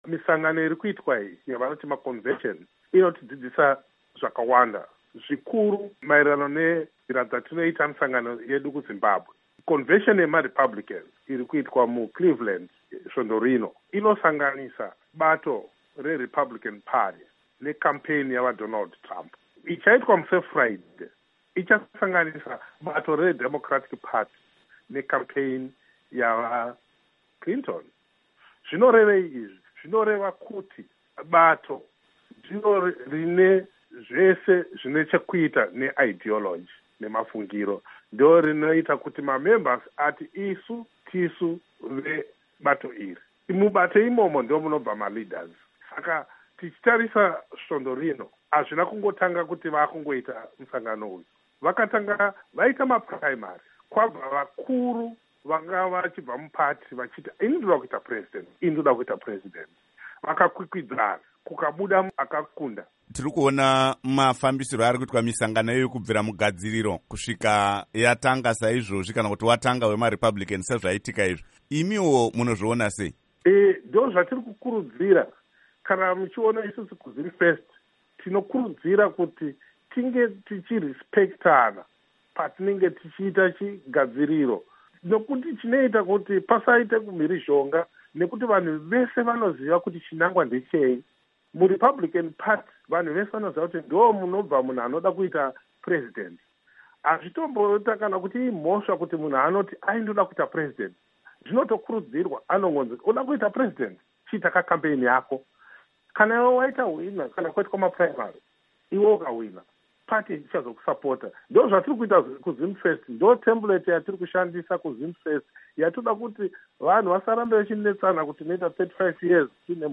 Zvimwe Zvizvarwa zveZimbabwe Zvodzidza Zvakawanda Kubva kuMusangano weRepublican Uri kuCleveleland, Ohio.